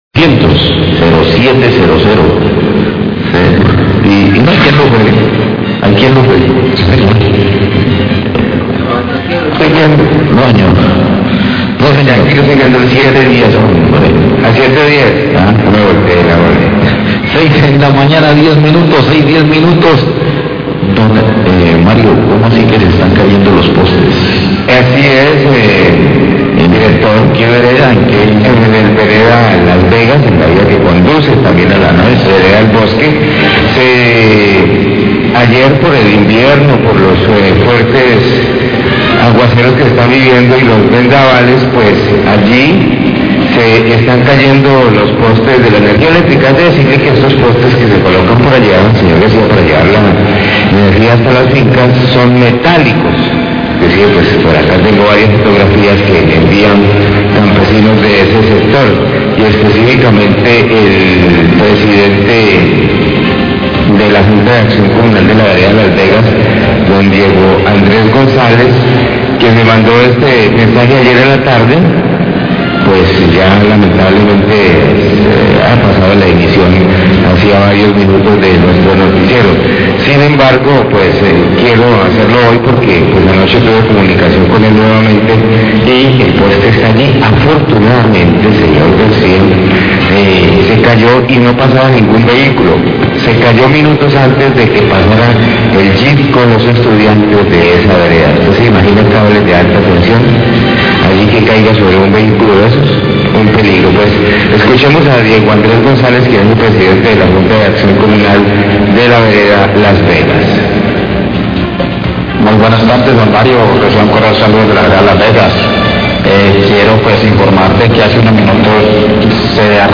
OYENTE REPORTA POSTE DE ENERGÍA CAIDO EN LA VEREDA LAS VEGAS ZONA RURAL DE TULUÁ, ALERTA VALLE DEL CAUCA, 6.10AM
Radio